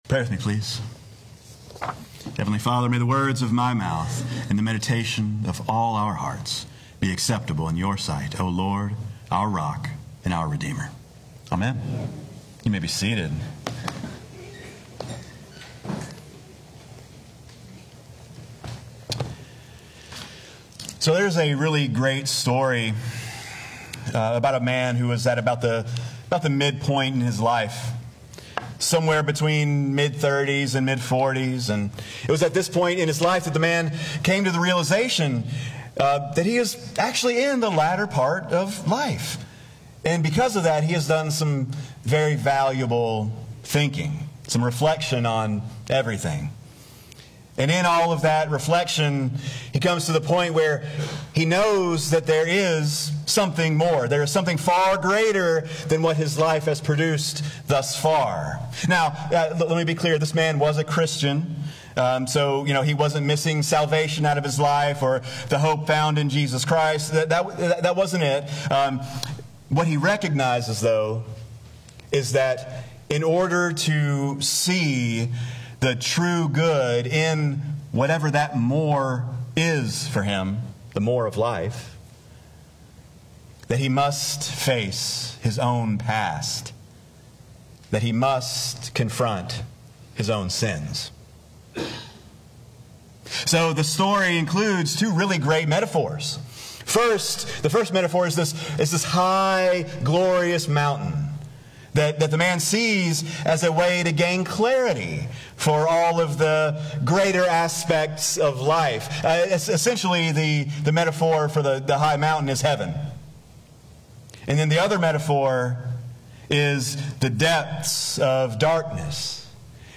Sermons | St. John's Parish Church